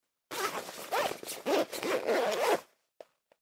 Звуки ширинки
8. Застежка-молния на одежде